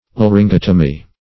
Search Result for " laryngotomy" : The Collaborative International Dictionary of English v.0.48: Laryngotomy \Lar`yn*got"o*my\, n. [Gr.